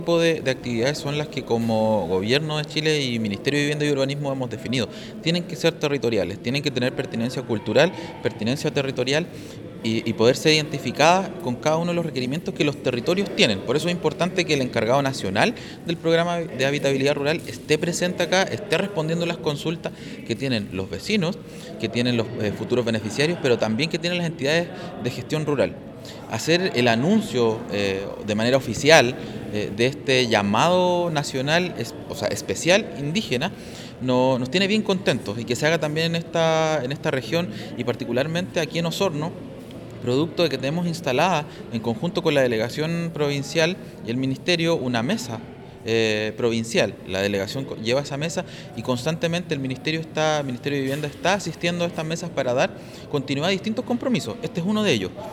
El Seremi de Vivienda y Urbanismo, Fabián Nail señaló que estos programas vienen a responder a las necesidades de los distintos territorios de la Región de Los Lagos, que había sido planteada en la Mesa de Asuntos Indígenas de la Provincia de Osorno.